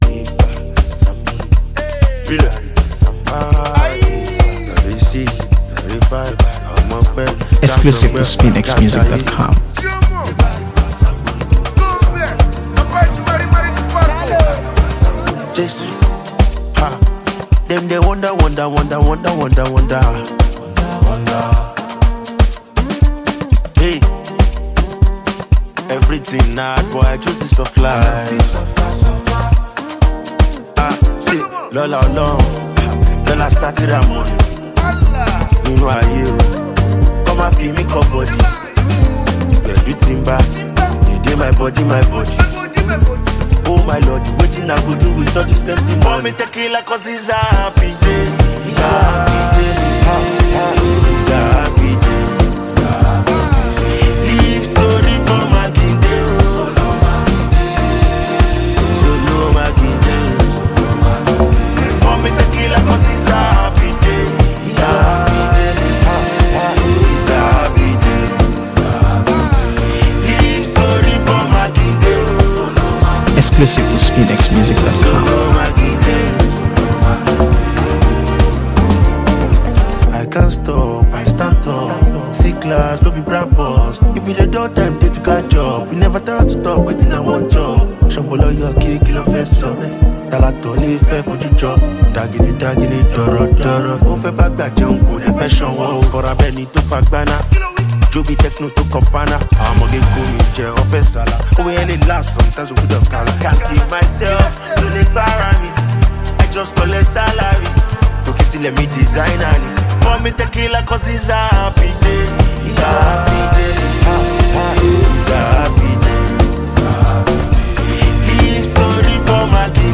AfroBeats | AfroBeats songs
With his signature blend of Afrobeats and street-hop
charismatic delivery and catchy hooks